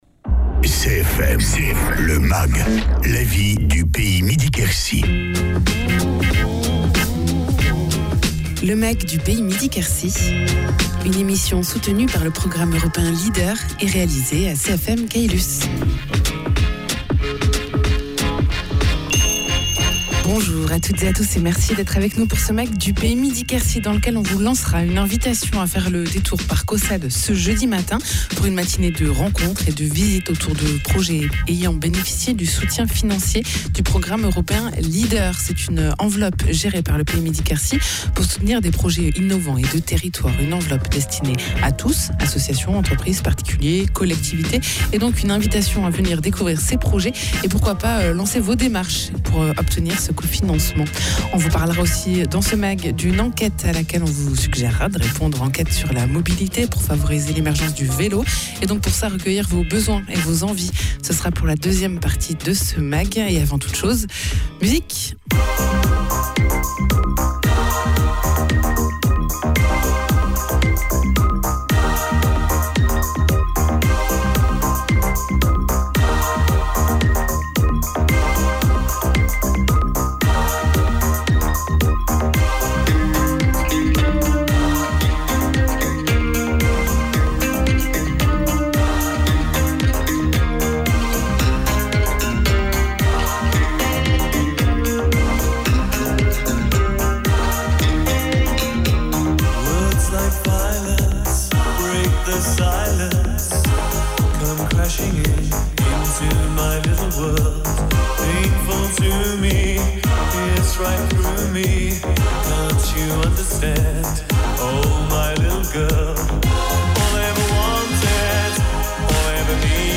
Rendez-vous à Caussade le jeudi 5 Octobre pour une rencontre et des témoignages autour du financement de projet innovants et de territoire grâce au financement européen LEADER. Egalement dans une mag, une enquête autour du nouveau schéma Directeur Cyclable qui vise à programmer le développement du vélo sur un territoire, en jouant sur trois piliers : les infrastructures, les services, la culture du vélo.